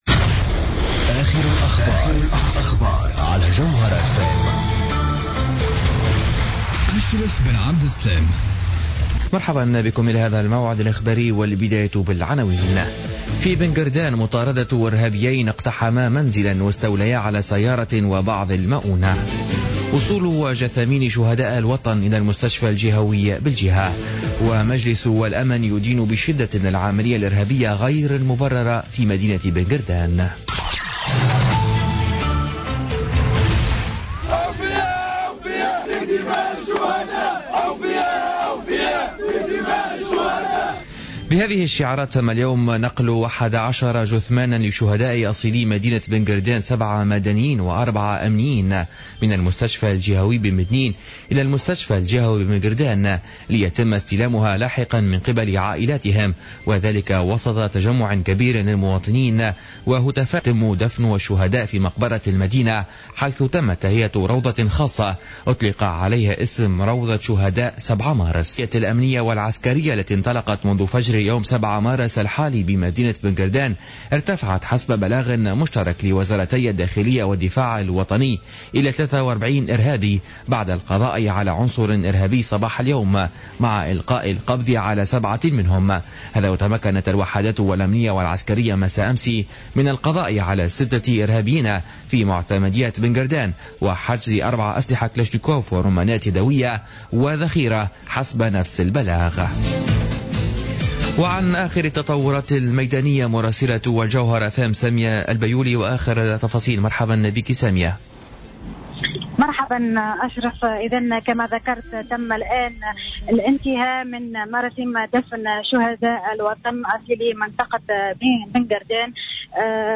نشرة أخبار منتصف النهار ليوم الأربعاء 9 مارس 2016